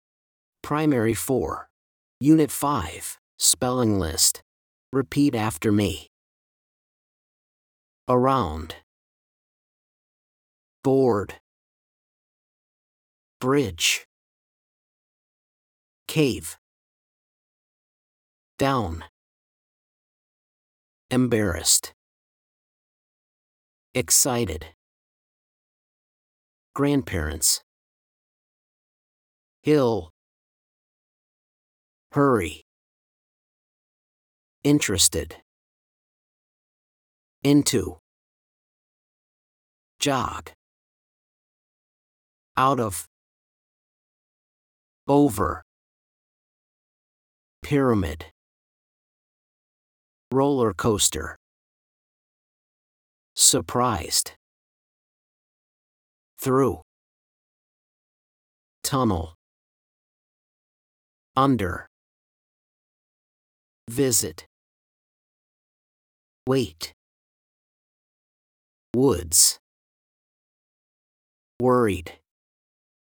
SPELLING LIST FOR UNIT 5
blankThese are the words on the spelling list. Listen and repeat after the teacher: